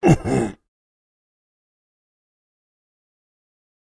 Creature_Sounds-Giant_Voice_1.ogg